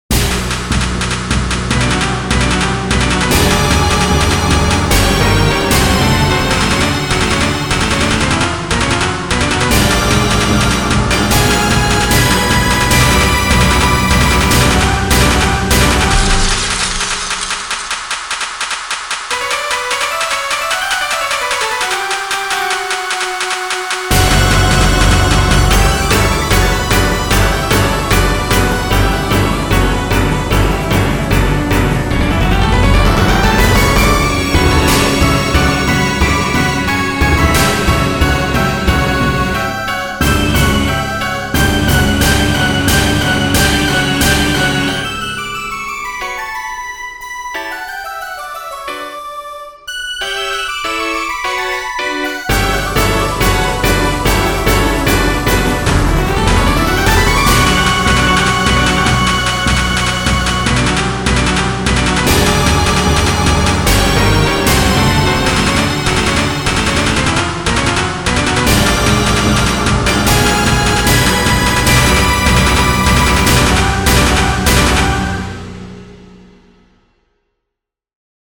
ショート激しい